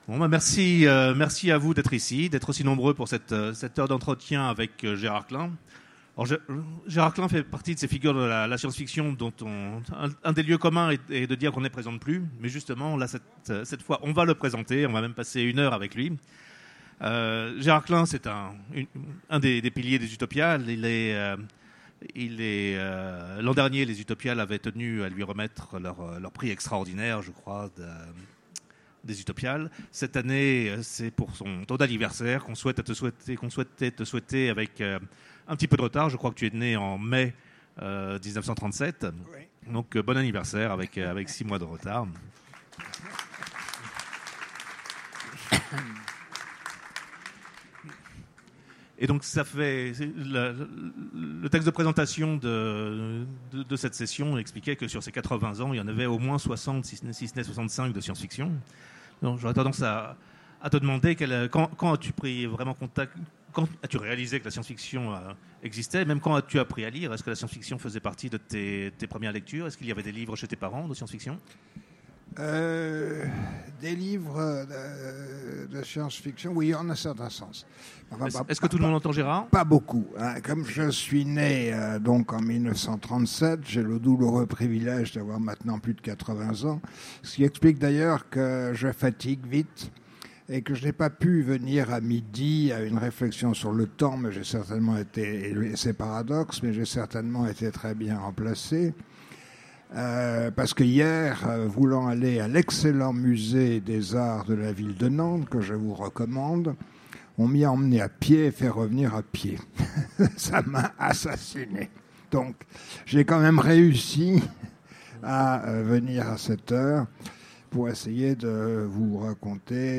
Utopiales 2017 : Conférence 80 ans de science-fiction